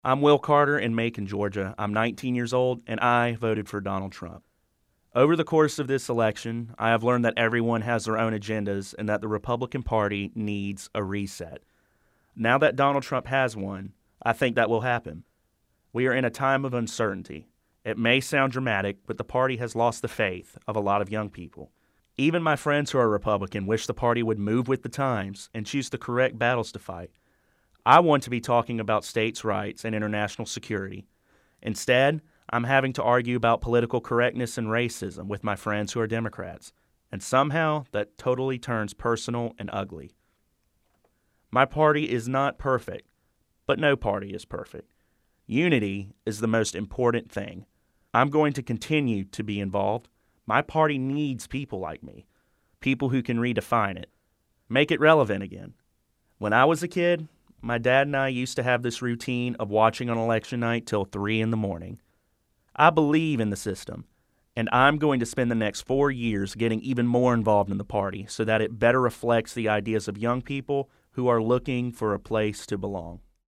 PresTrump_Essay.mp3